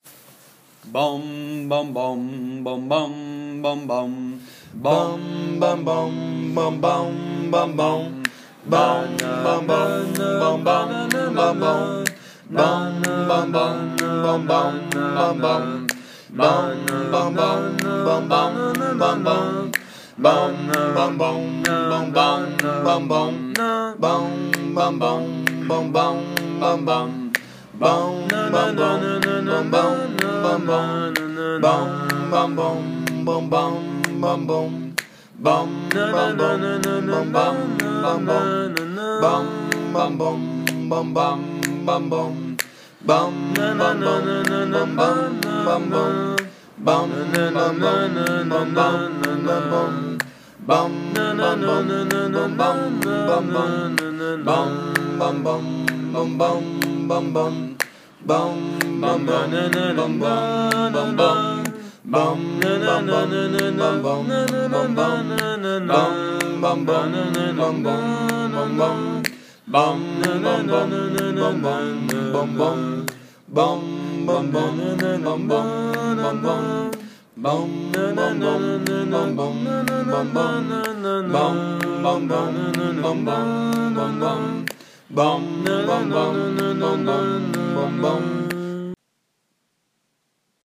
A capella, le retour
Il faut bien avouer que nous prenons toujours un plaisir fou à reprendre des airs à la seule force de nos voix.